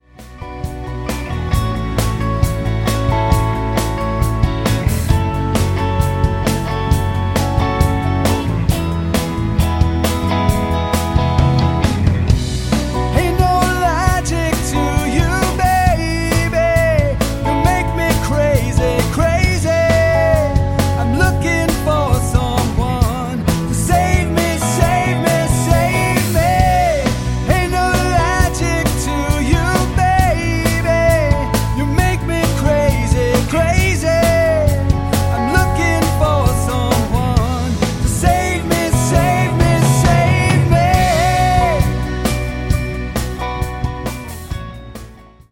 old school rock, funk and R&B
guitar
keyboards
wistfully haunting vocals